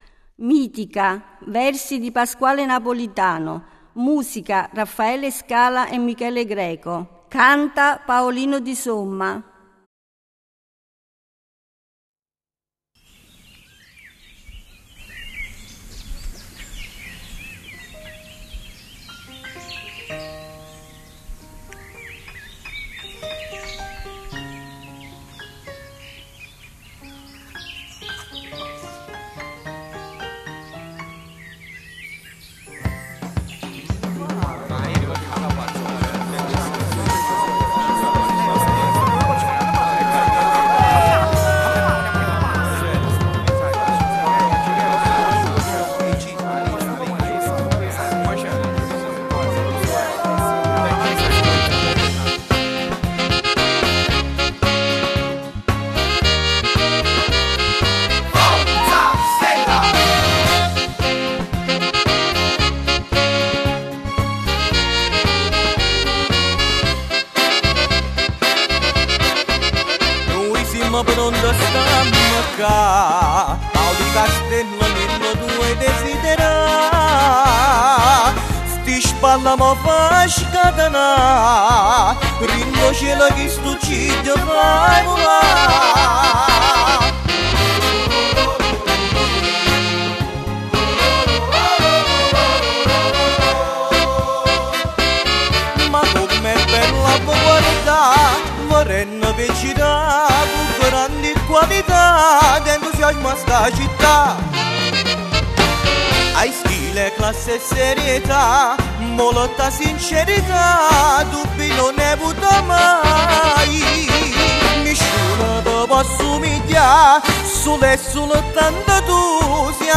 Giglio del Panettiere 2009